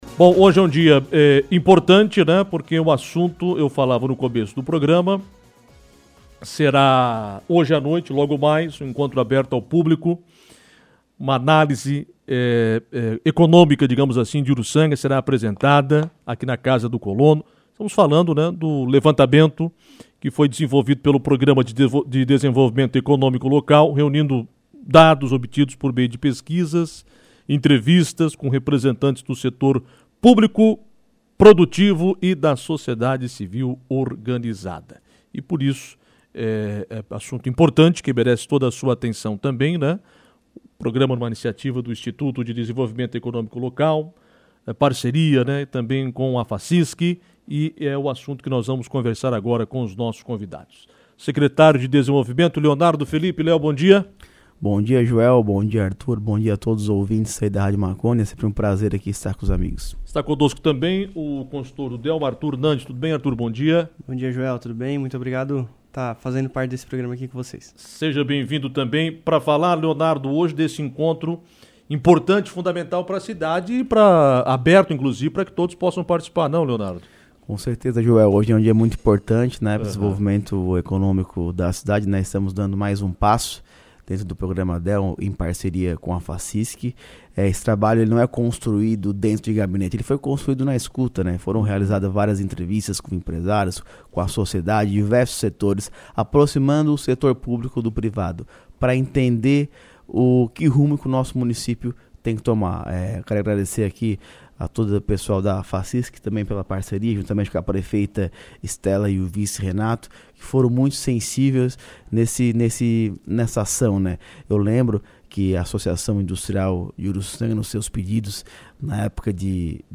entrevista no programa Comando Marconi